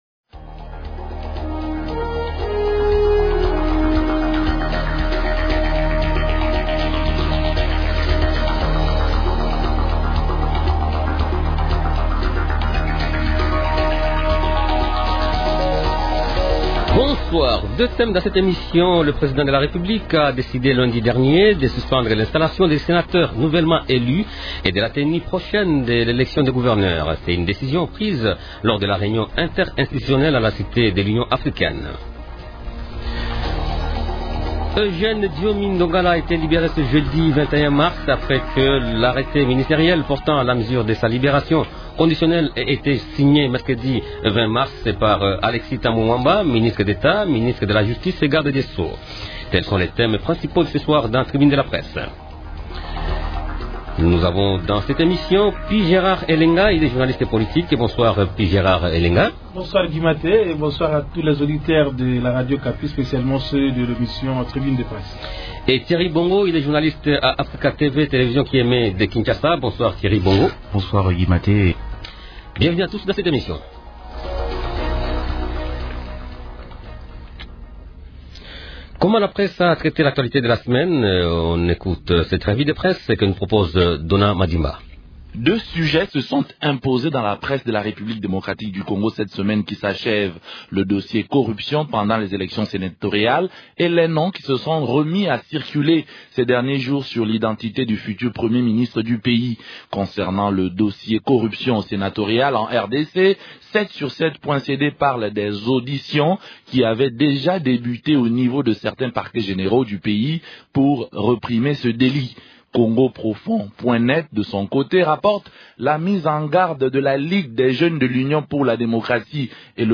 Deux thèmes ont été débattus au cours de la tribune de la presse du vendredi 22 mars :